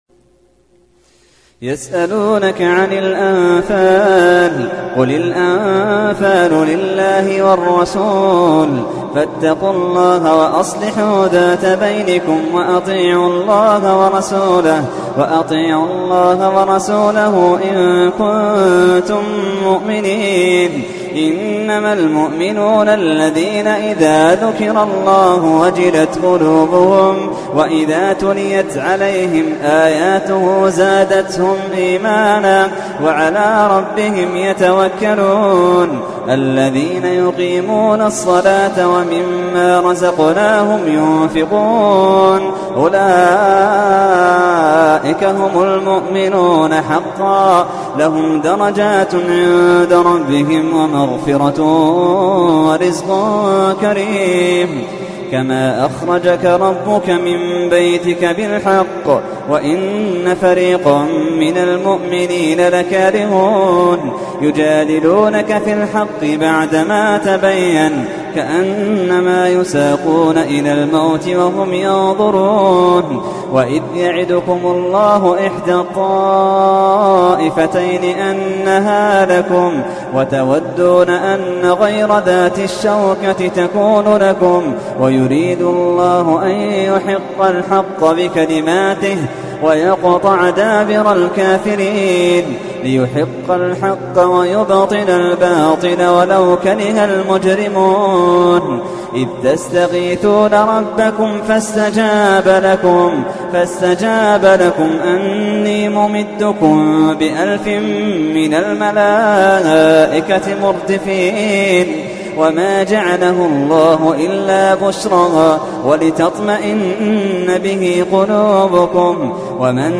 تحميل : 8. سورة الأنفال / القارئ محمد اللحيدان / القرآن الكريم / موقع يا حسين